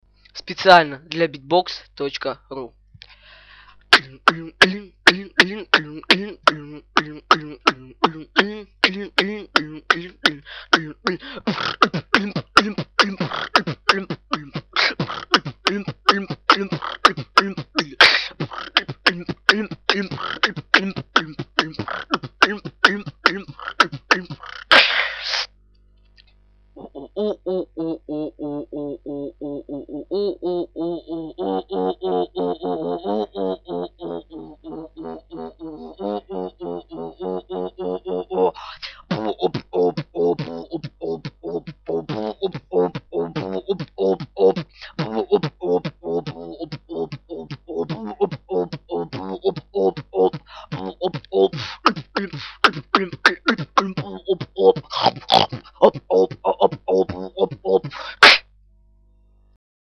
мой легкий фристаил)))
коментируем мой битбокс и говорим над чем надо пороботать я битую только 1 месяц так что на меня сильно неорите))))) biggrin
Поработать, конечно, нужно со всеми звуками, с ритмом, ведь всё равно нужно развиваться)
Да поработать еще много придеться)Особено с ууууу уууу уу!
горловой тренируй)